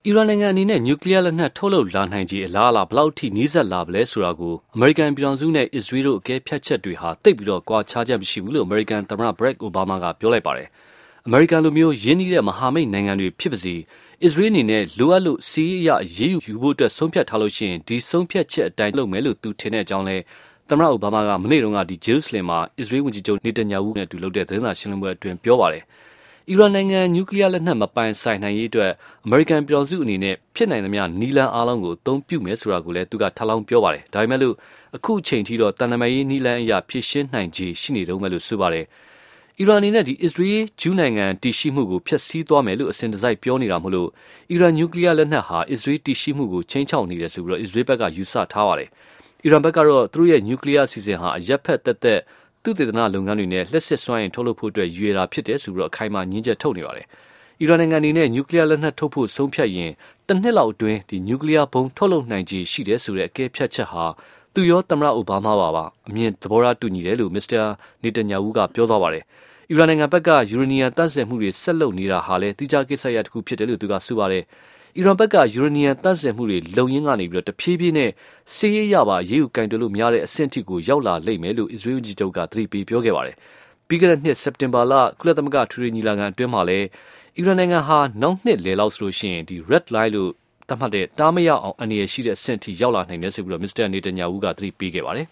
အိုဘားမား အစ္စရေးလ် သတင်းစာရှင်းလင်းပွဲ